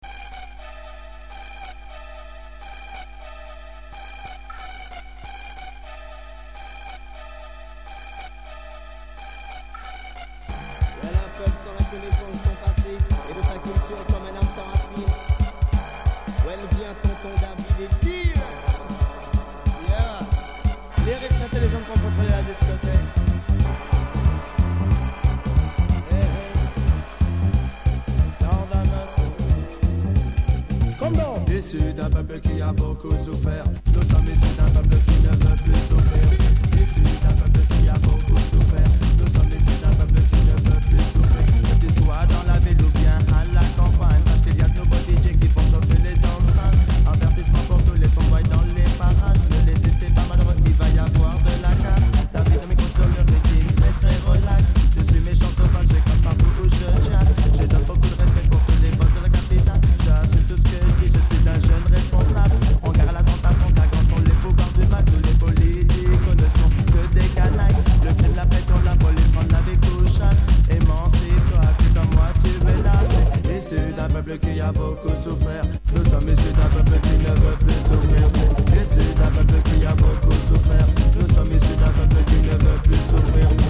Jungle